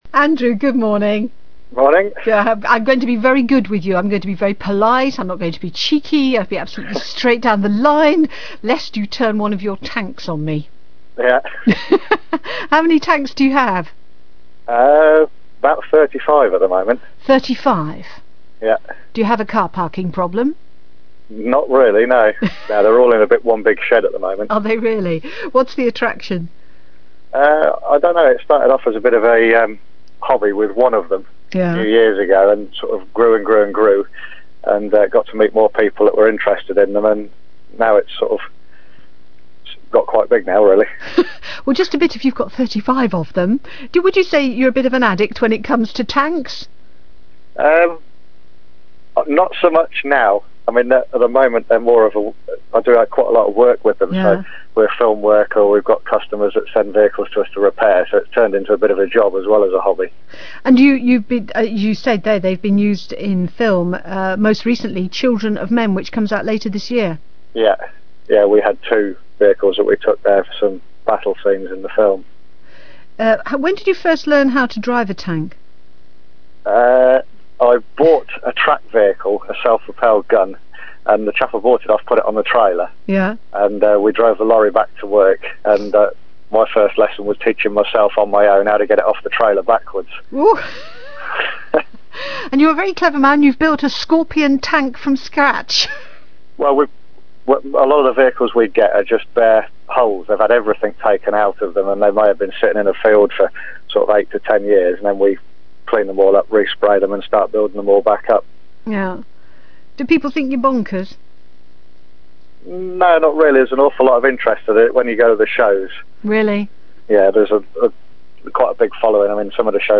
11th August 2006 - BBC Coventry and Warwick Radio interview